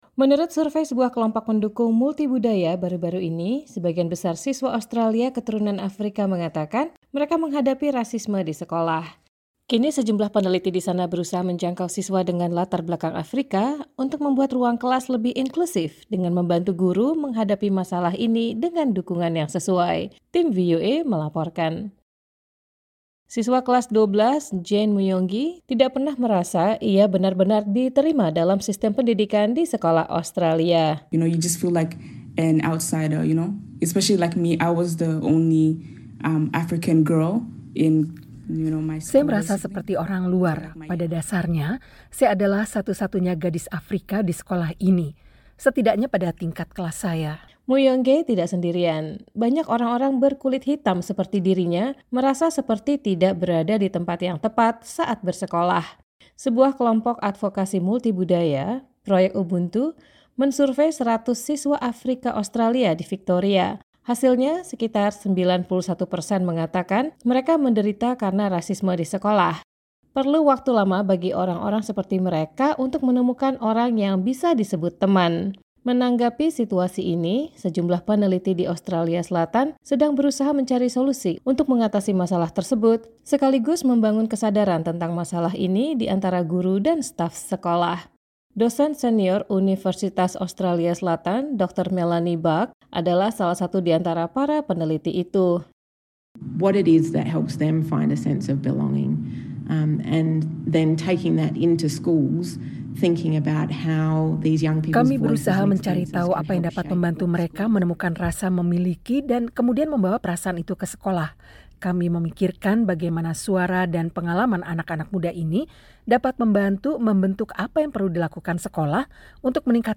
Kini, sejumlah peneliti di sana berusaha menjangkau siswa dengan latar belakang Afrika untuk membuat ruang kelas lebih inklusif. Tim VOA melaporkan.